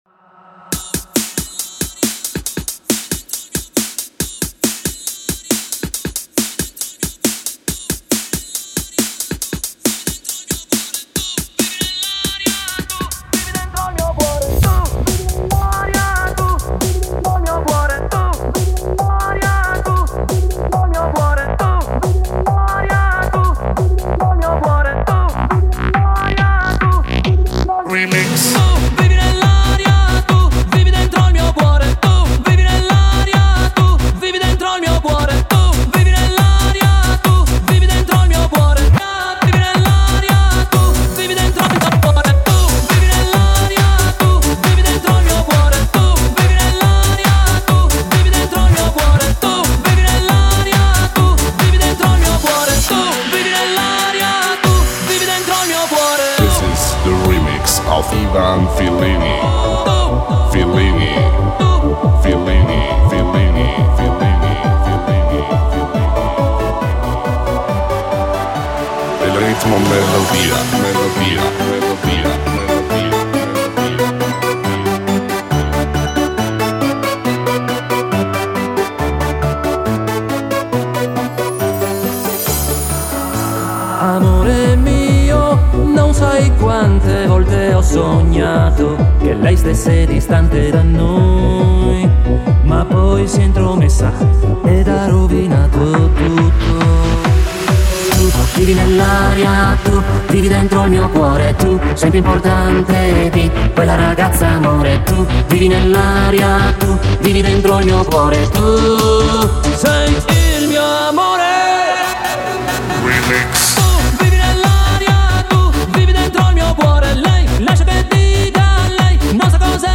Italo Dance
Hard Dance Style